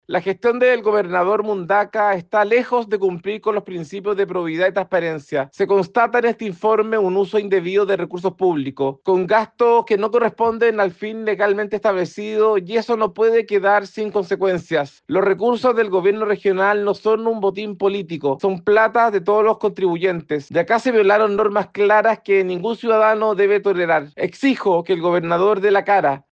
El diputado Andrés Celis (RN) dijo que la gestión de Rodrigo Mundaca “está lejos de cumplir con los principios de probidad y transparencia.